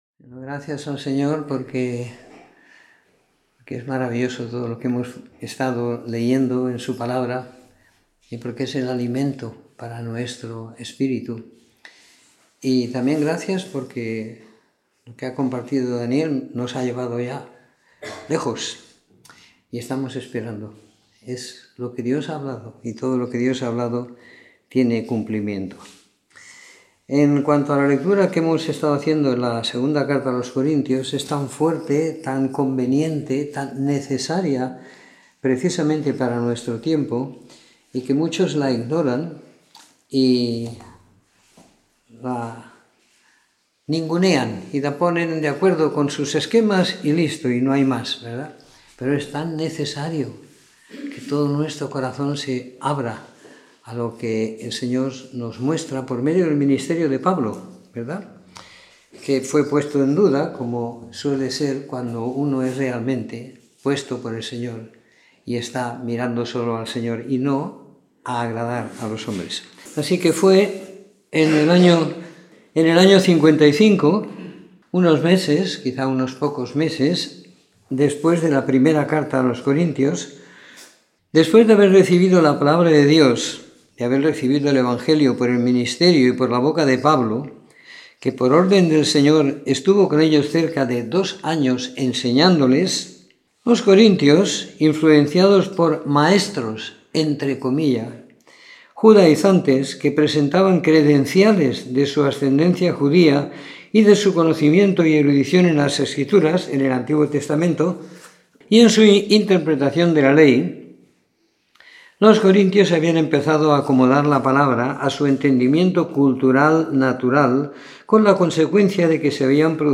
Comentario en la segunda epístola a los Corintios siguiendo la lectura programada para cada semana del año que tenemos en la congregación en Sant Pere de Ribes.